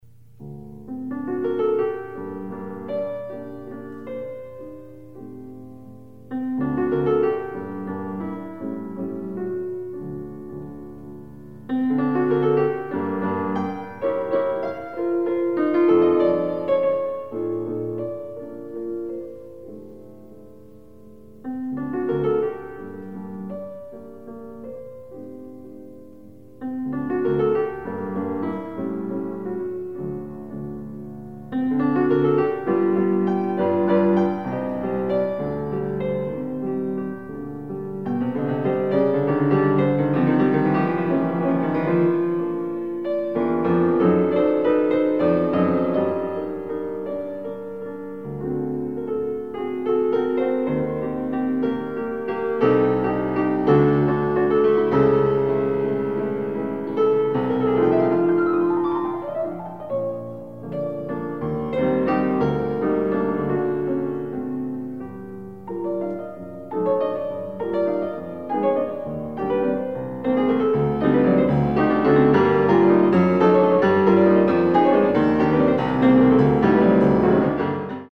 Soloist
Recorded September 15, 1977 in the Ed Landreth Hall, Texas Christian University, Fort Worth, Texas
Ballades (Instrumental music)
performed music